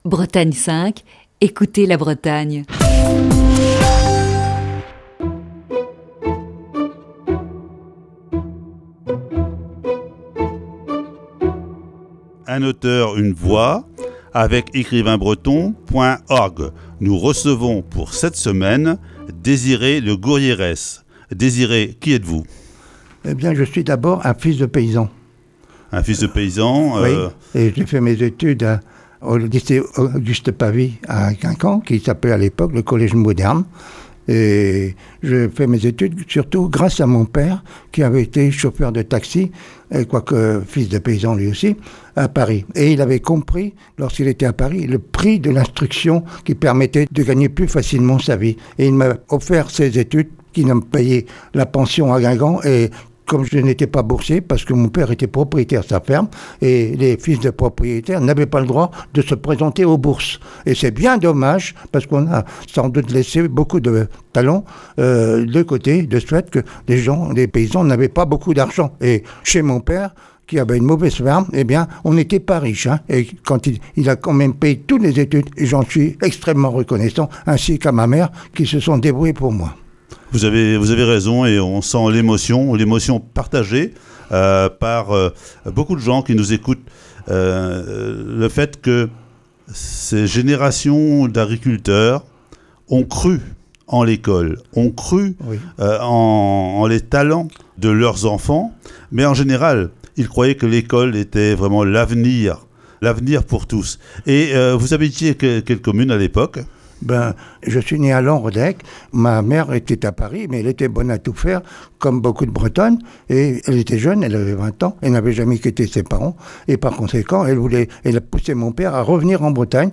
Voici ce lundi, la première partie de cette série d'entretiens.